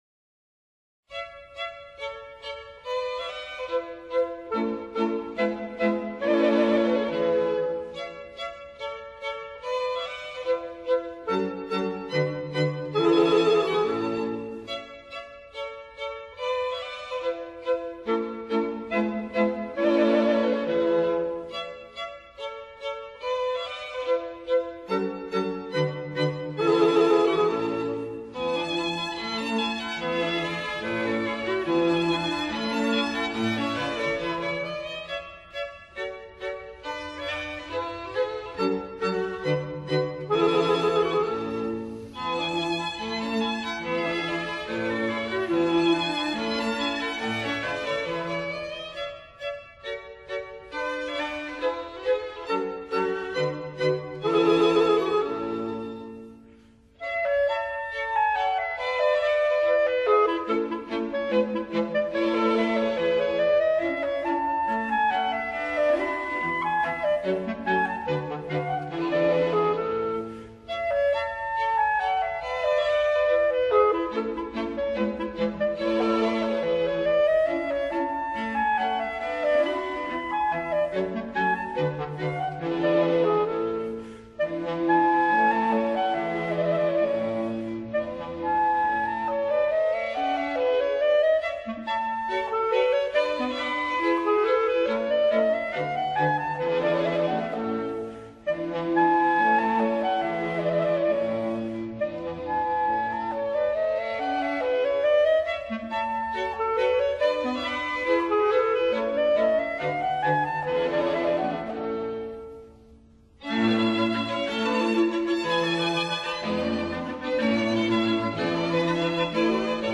in A major, KV.581
오히려 수채화처럼 맑고, 그리고 우아하면서도 독특한 애수가 애잔하게 흐르는 감동이 있습니다.
유려하게 흘러가는 현악 선율을 바탕으로 로맨티시즘을 가득 담은 우수의 클라리넷 선율은
편성은 클라리넷, 바이올린2, 그리고 비올라와 첼로를 사용했습니다.
Karl Leister, Clarinet
The Vienna String Quartet
독일 전통의 차분하게 가라앉은 은근한 음향이 무척 매력적입니다.